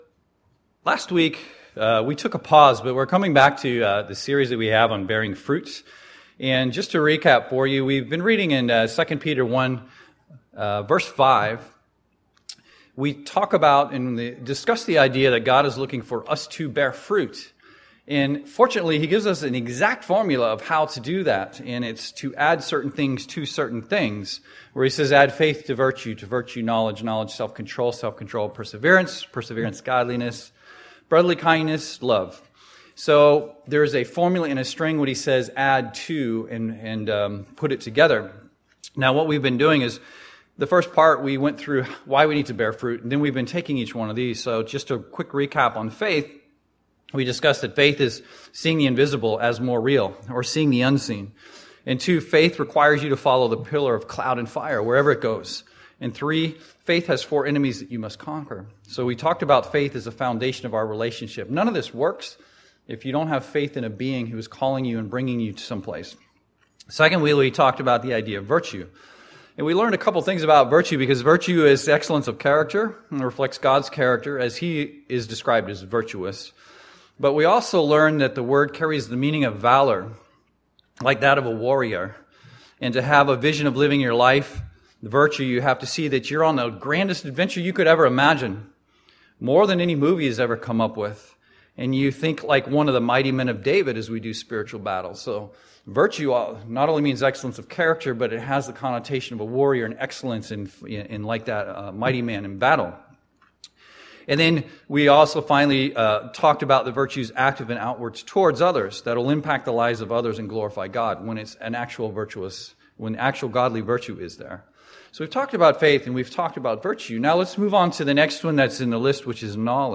Sermons
Given in Seattle, WA